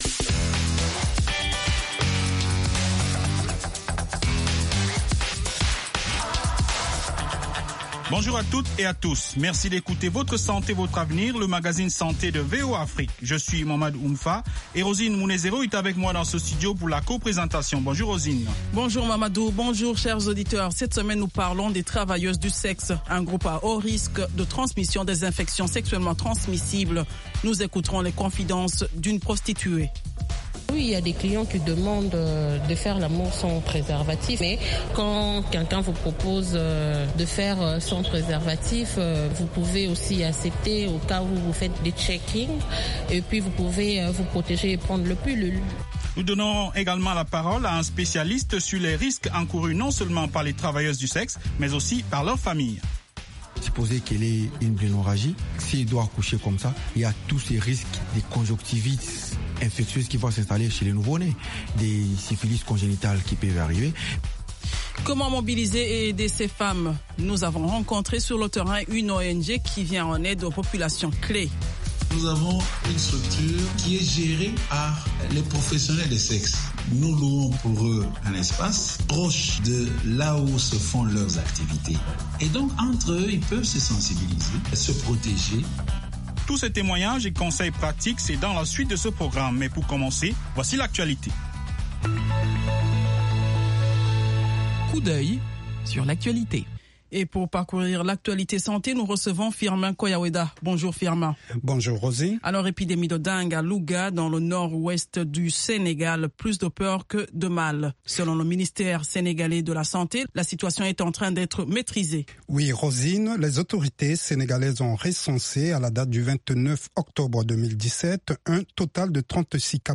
Fistules etc. Avec les reportages de nos correspondants en Afrique. VOA donne la parole aux personnes affectées, aux médecins, aux expert, aux parents de personnes atteintes ainsi qu’aux auditeurs.